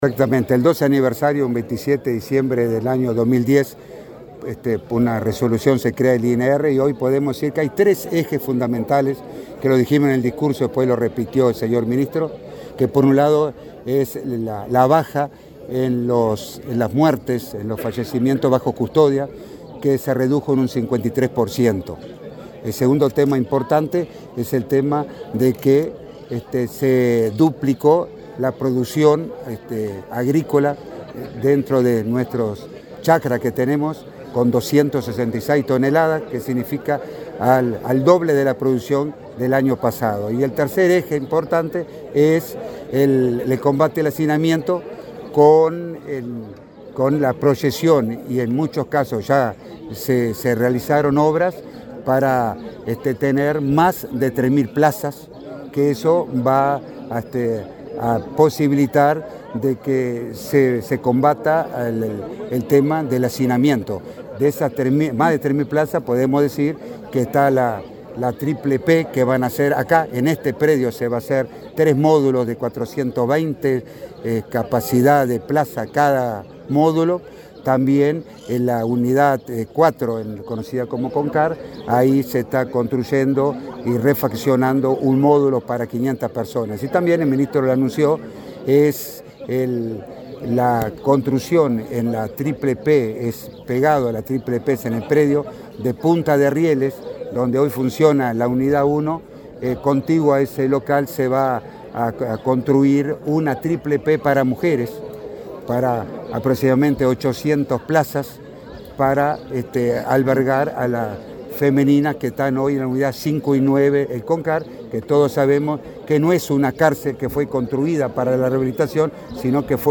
Declaraciones a la prensa del director del INR, Luis Mendoza
Declaraciones a la prensa del director del INR, Luis Mendoza 26/12/2022 Compartir Facebook X Copiar enlace WhatsApp LinkedIn El director del Instituto Nacional de Rehabilitación (INR), Luis Mendoza, y el ministro Luis Alberto Heber participaron del acto por el 12.° aniversario de esa dependencia, en la Unidad N.°2 en el departamento de San José. Luego, Mendoza dialogó con la prensa.